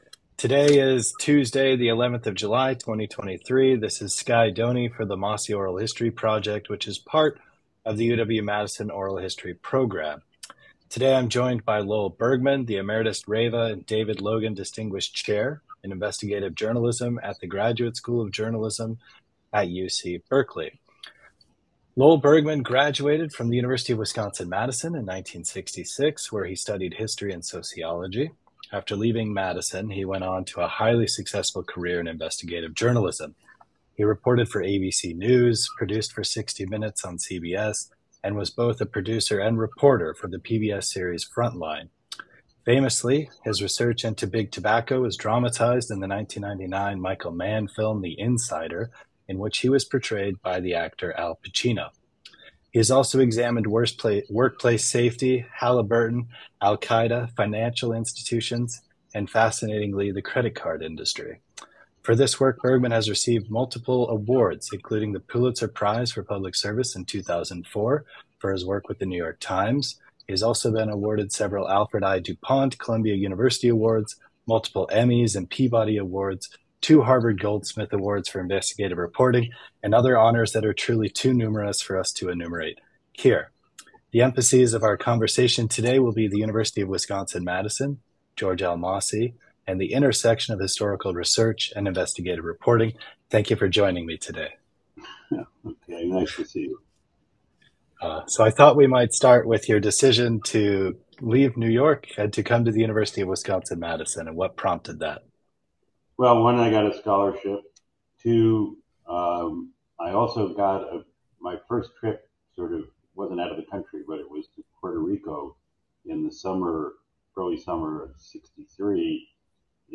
Interview with Lowell Bergman